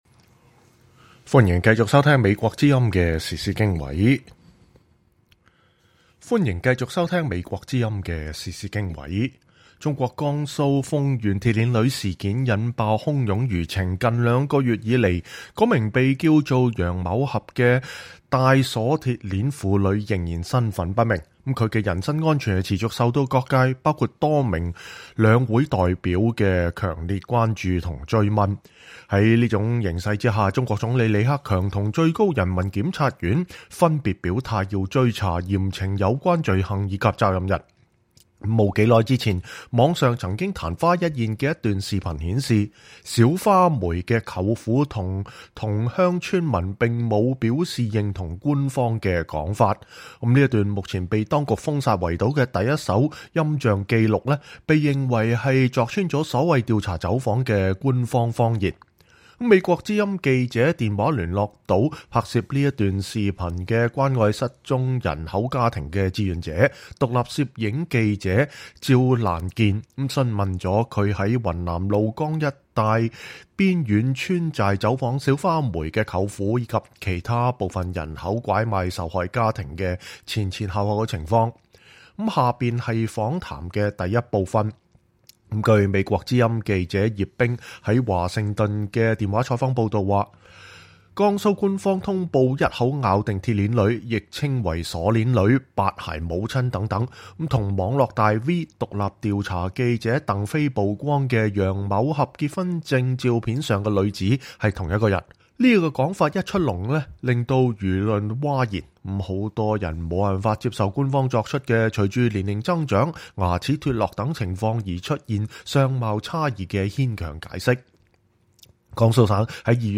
這是訪談的第一部分。